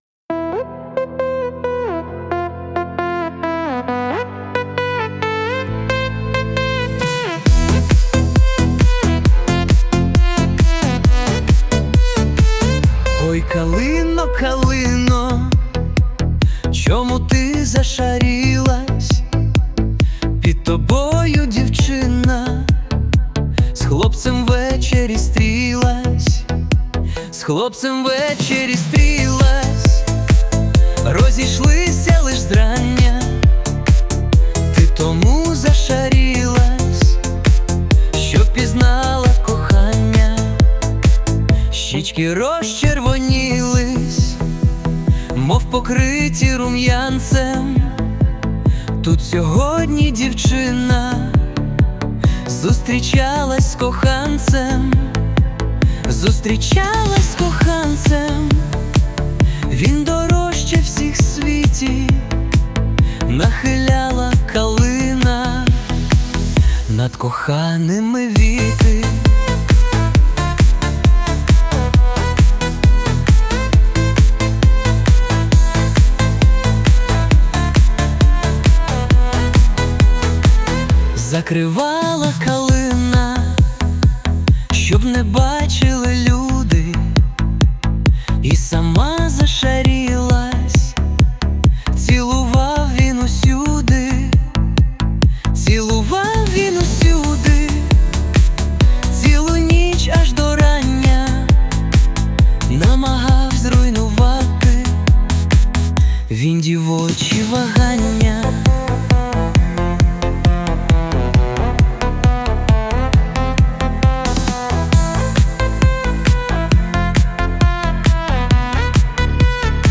Ой калино (естрада)
ТИП: Пісня
СТИЛЬОВІ ЖАНРИ: Ліричний